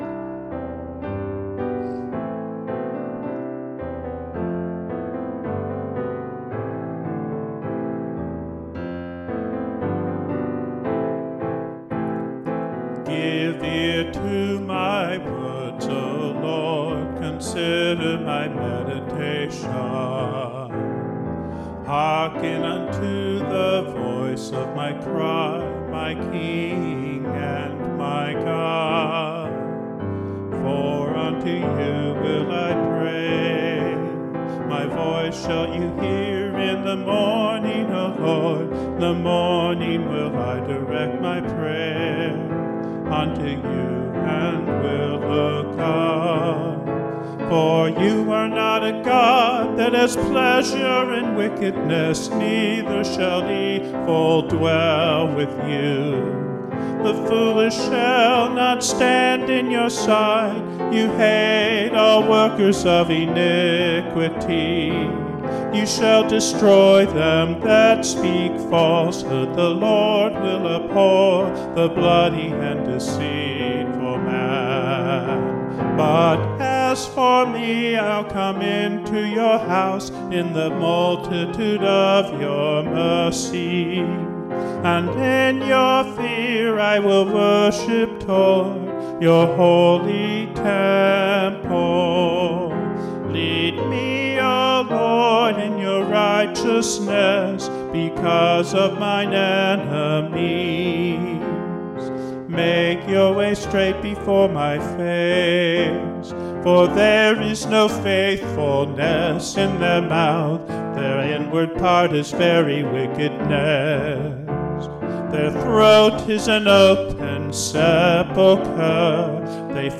OGG PDF ABC 2026 Early One Take recording